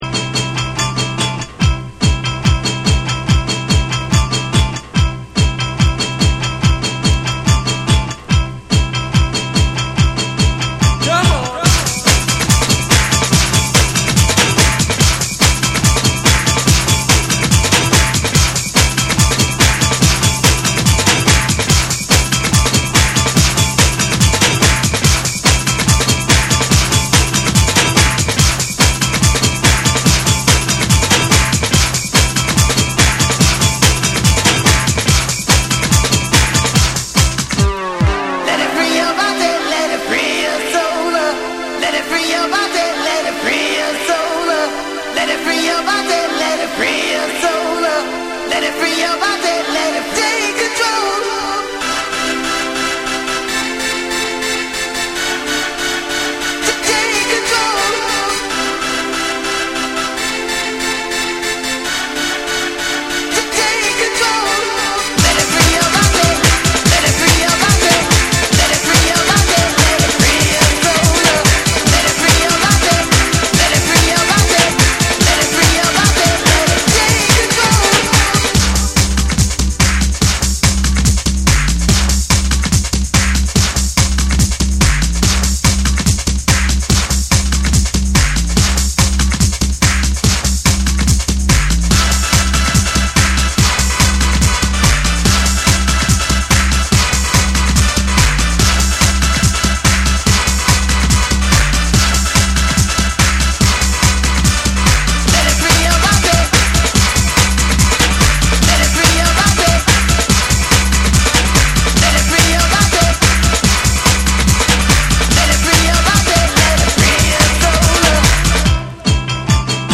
ヘヴィーウェイトでリズミカルなリズムに、レイビーなシンセやヴォイス・サンプルが絡み展開するブレイクビーツを収録！
BREAKBEATS